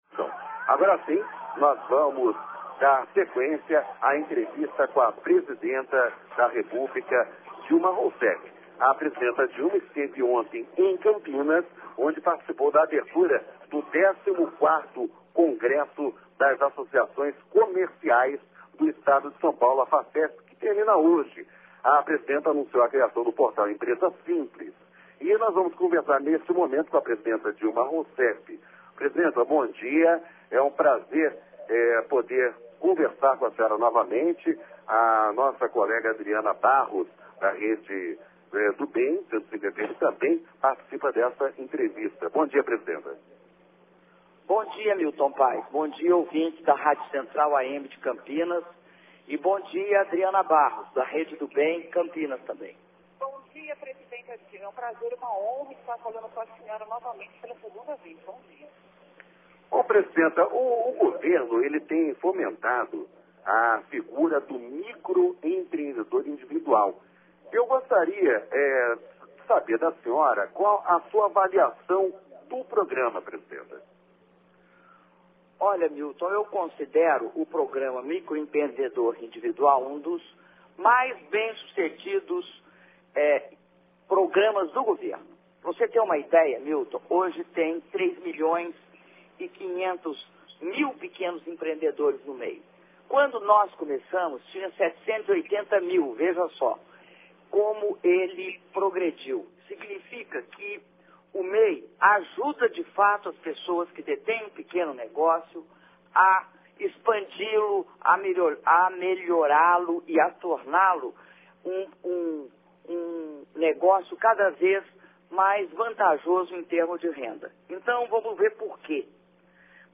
Áudio da entrevista da Presidenta da República, Dilma Rousseff, às rádios Central AM e Rede do Bem FM, da cidade de Campinas-SP